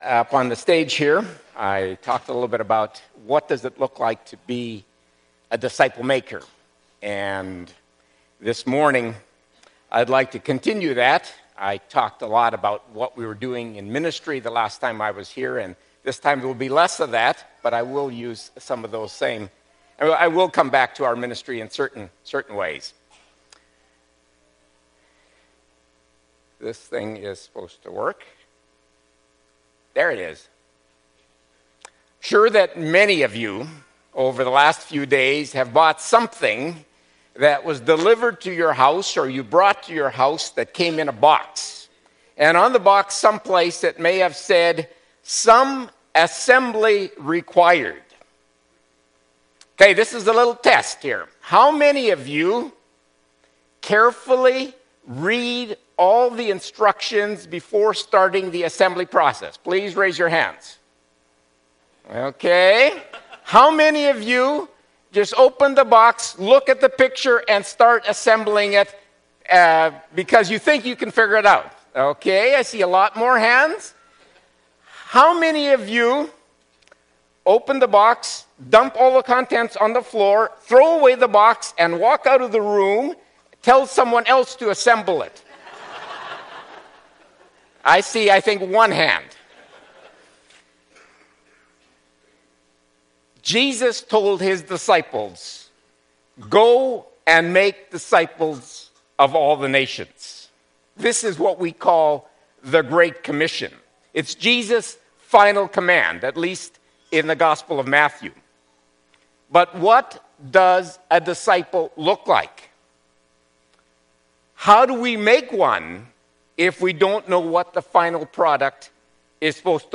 Series: Guest Preachers